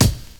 BD 07.wav